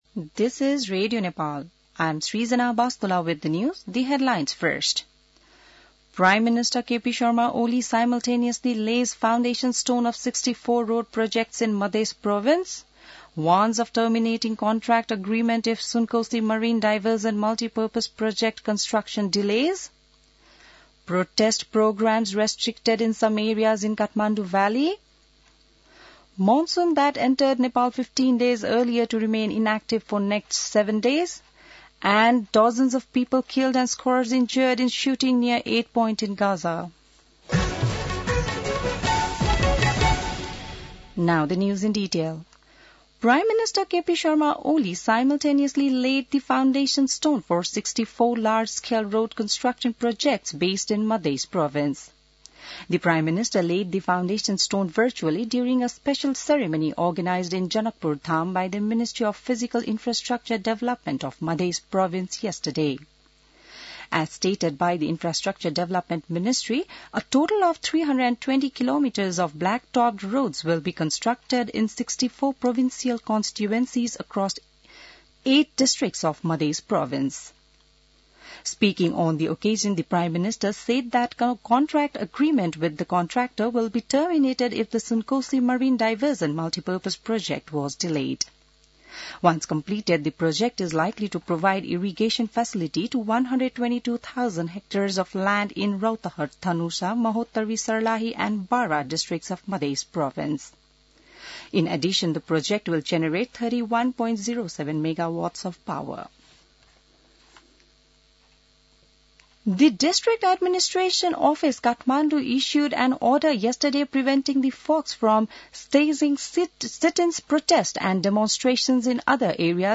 बिहान ८ बजेको अङ्ग्रेजी समाचार : १९ जेठ , २०८२